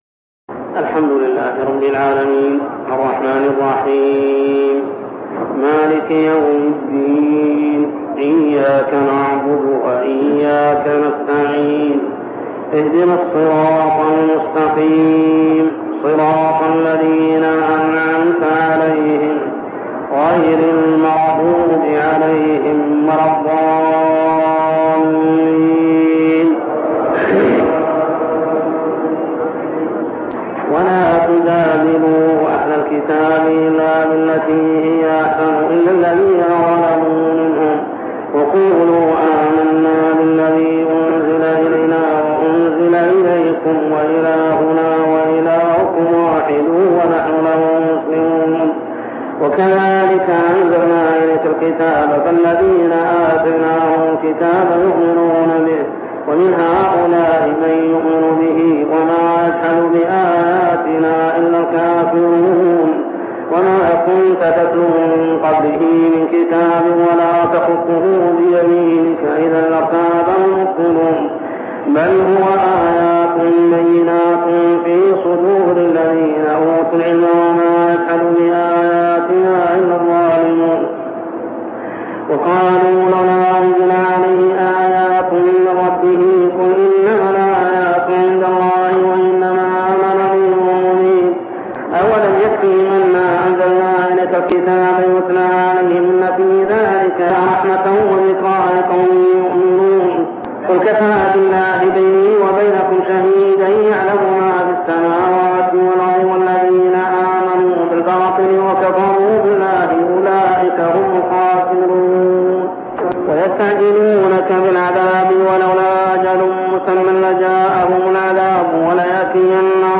صلاة التراويح عام 1402هـ سور العنكبوت 46-69 و الروم كاملة و لقمان 1-11 | Tarawih prayer Surah Al-Ankabut, Ar-Rum, and Luqman > تراويح الحرم المكي عام 1402 🕋 > التراويح - تلاوات الحرمين